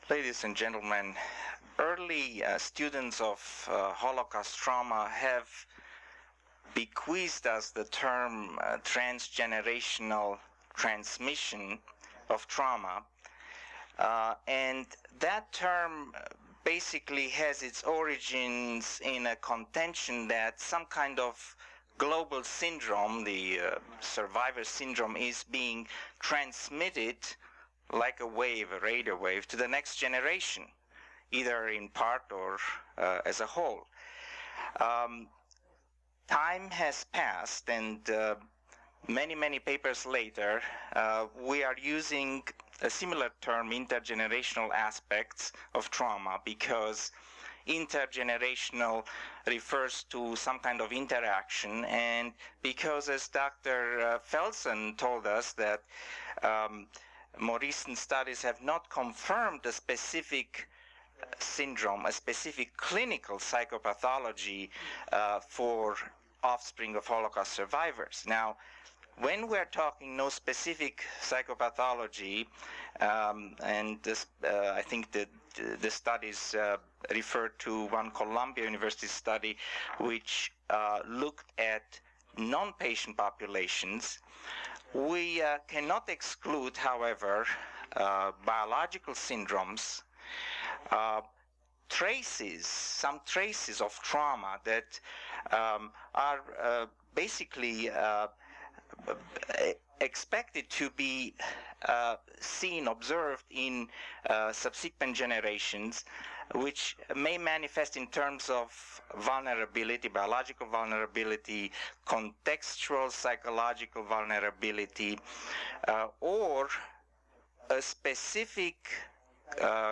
APA Annual Meeting